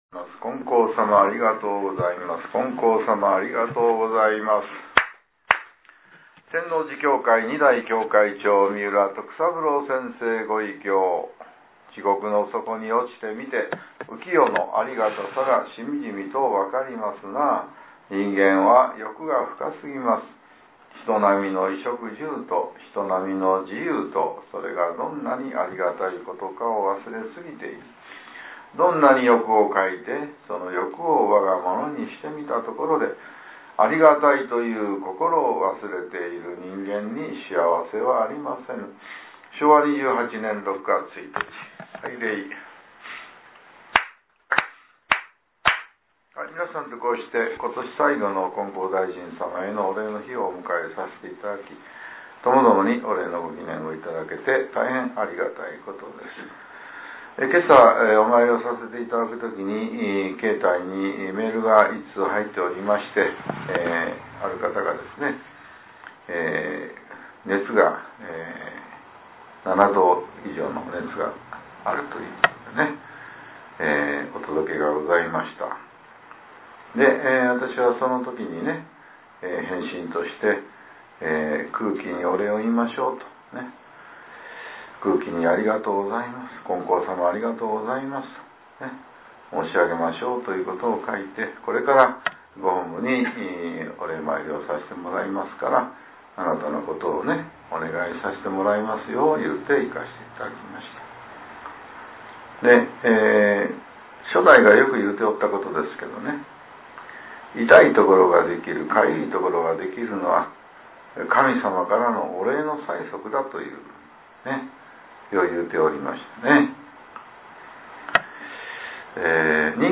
令和６年１２月１３日（朝）のお話が、音声ブログとして更新されています。